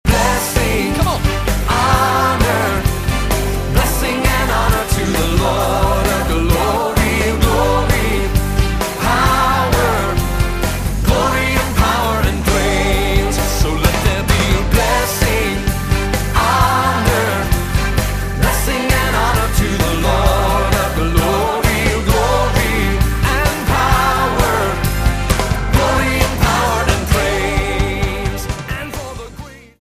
STYLE: Pop
definite jazz-gospel style